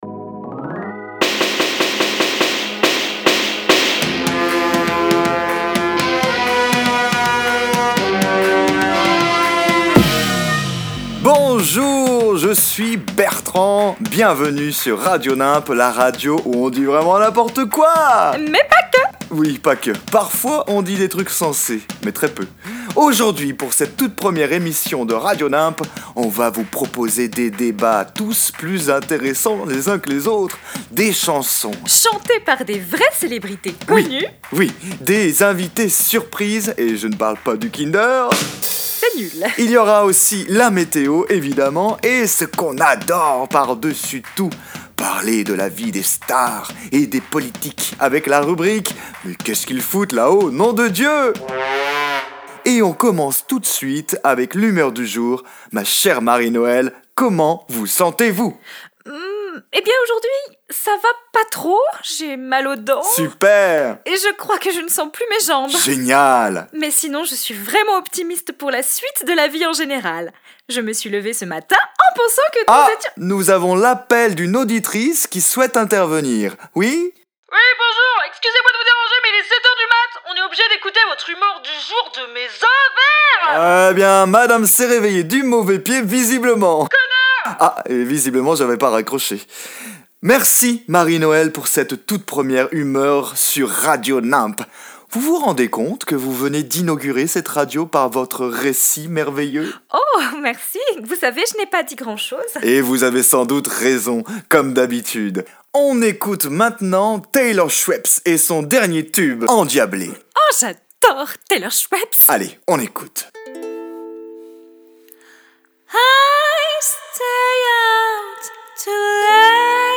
radio parodique
Cette plateforme se fait plaisir en parodiant les codes des émissions radiophoniques, mais tient à rester avant tout un exercice théâtral. Les artistes de So Castafiore se saisissent de ce média pour proposer des rubriques improbables, inviter des stars internationales, créer des personnages farfelus et laisser libre court à leur imagination foisonnante.